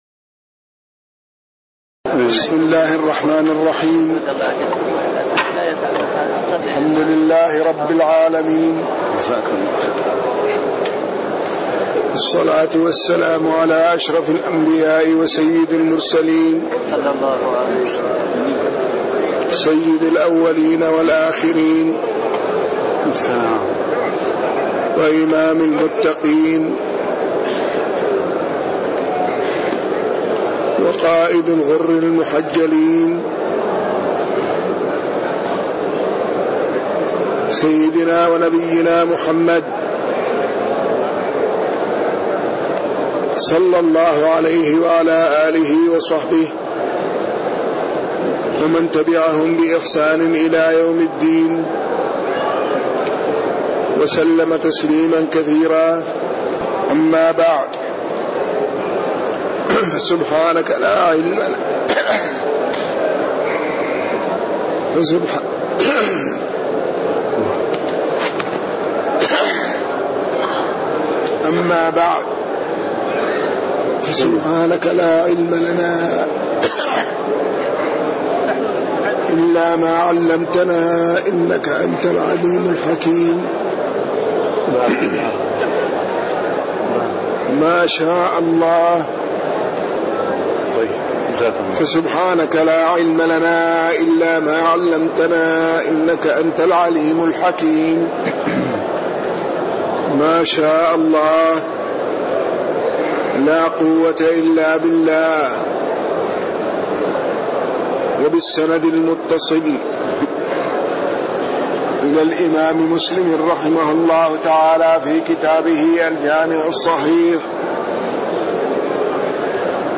تاريخ النشر ١ ربيع الثاني ١٤٣٤ هـ المكان: المسجد النبوي الشيخ